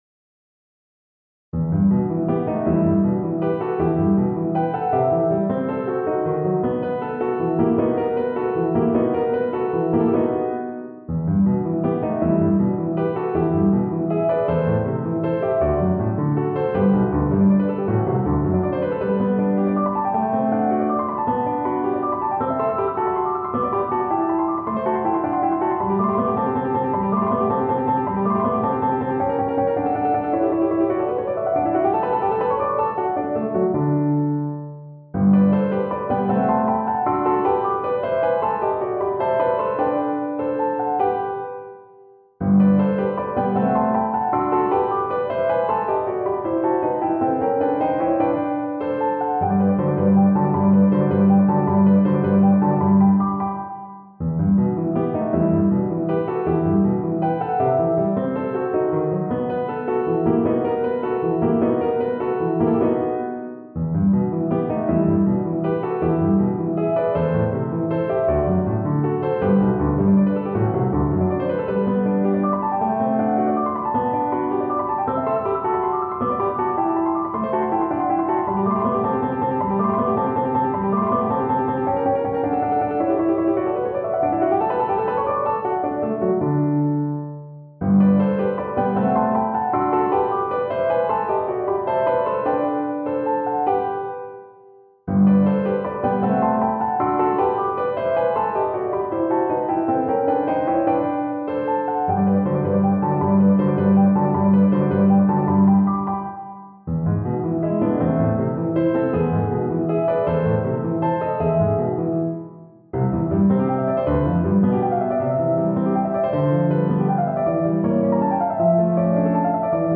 No parts available for this pieces as it is for solo piano.
Instrument: Piano
Style: Classical